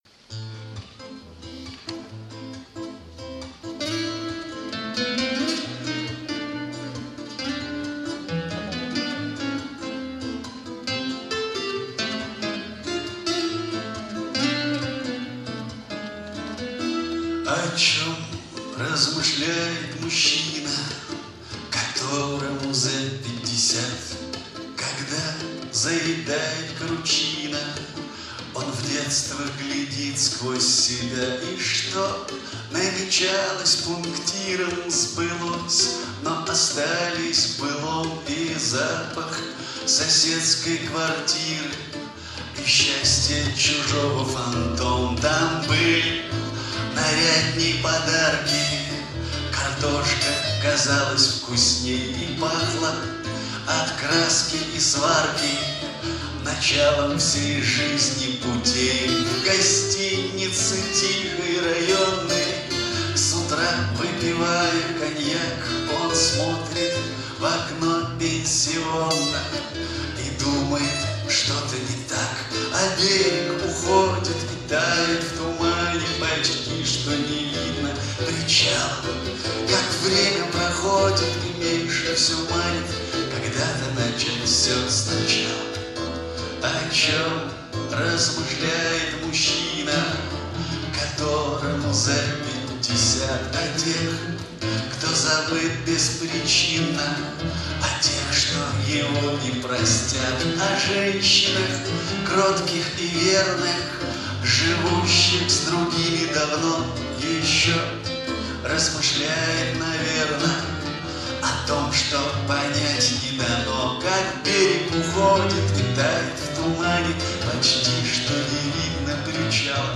с концерта